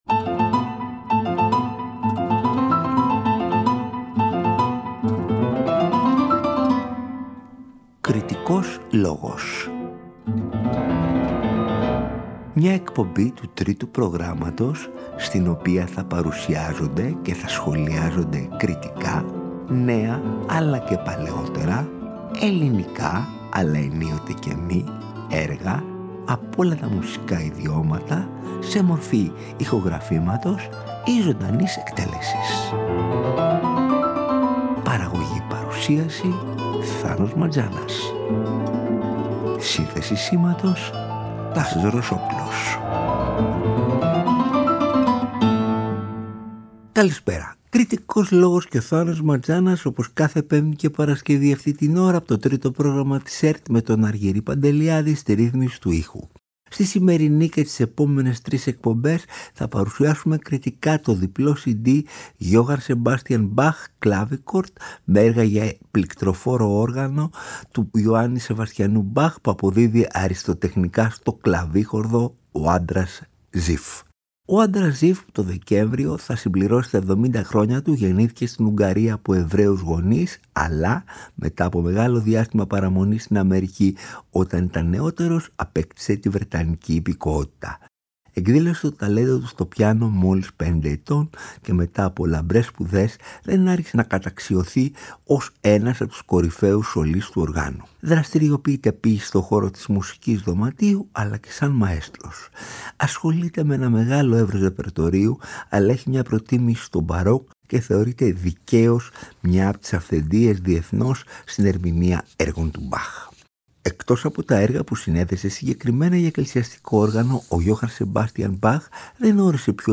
με έργα για πληκτροφόρο όργανο
κλαβίχορδο